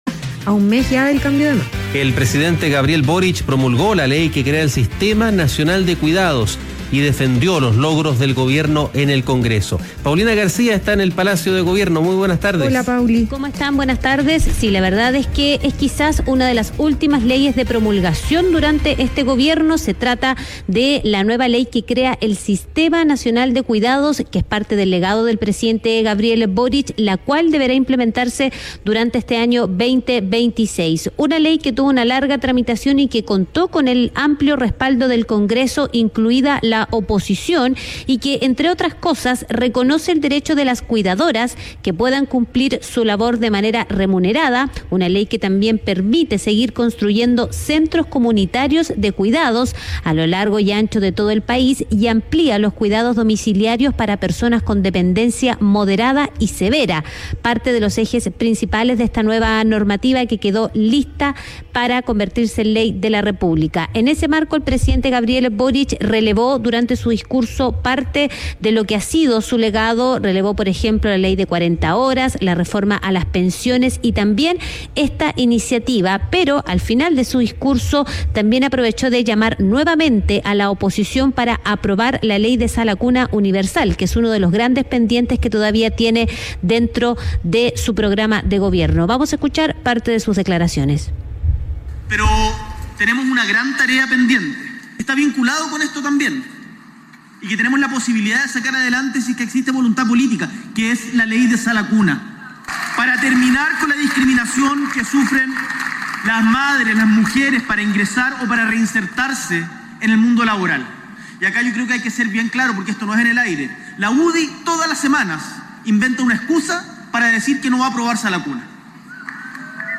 Durante la ceremonia de Chile Cuida, el mandatario acusó a la oposición de bloquear la normativa de sala cuna por intereses electorales y exigió su aprobación para marzo.